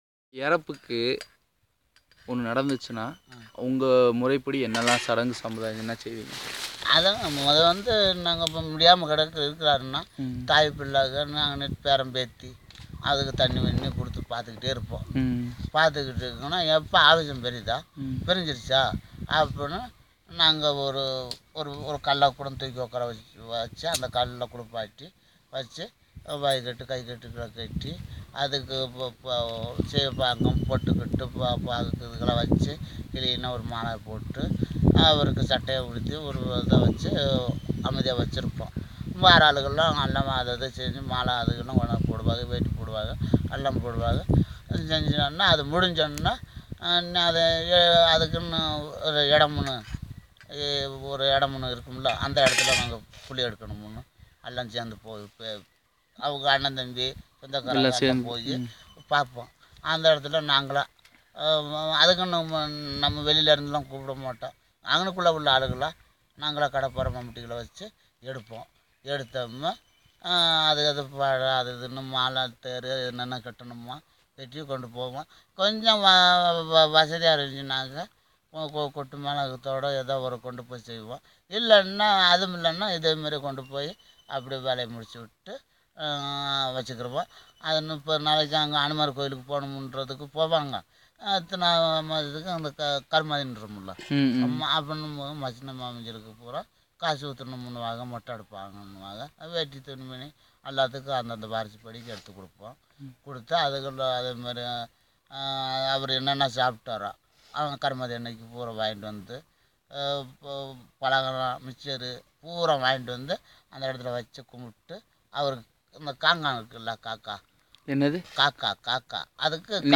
Conversation about death ceremony